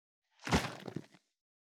341ワインボトルを振る,水の音,ジュースを振る,シャカシャカ,カシャカシャ,チャプチャプ,ポチャポチャ,シャバシャバ,チャプン,ドボドボ,
ペットボトル